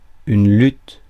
Ääntäminen
IPA: [lyt]